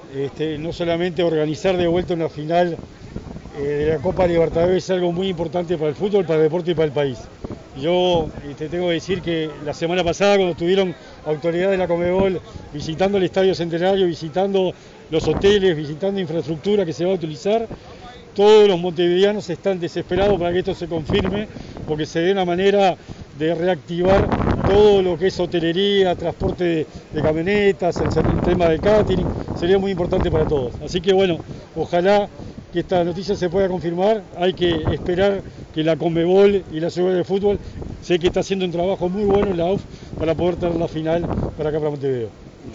El secretario realizó estas declaraciones en la entrega del pabellón nacional a los remeros Bruno Cetraro y Felipe Klüver, para su participación en los Juegos Olímpicos.